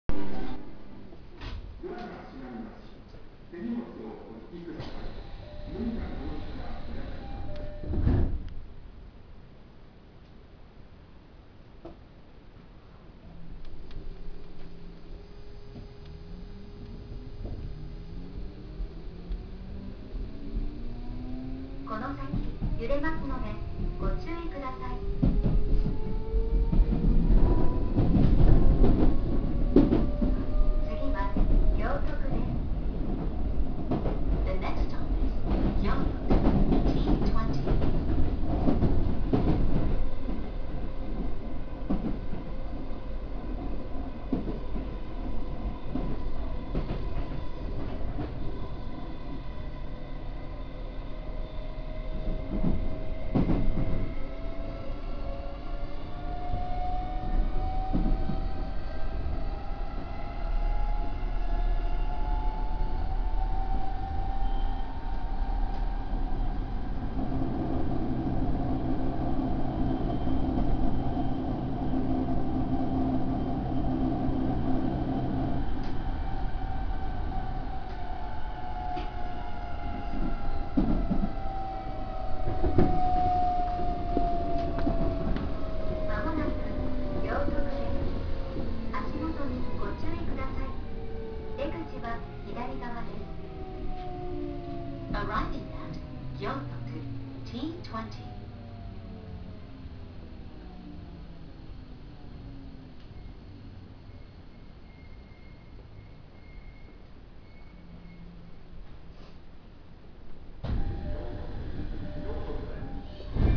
07系更新車走行音
更新後は三菱PMSMに変更されており、静粛性が増した代わりにモーター音は目立たなくなっています。ドアチャイムも所謂「JRタイプ」に変更されてしまいました。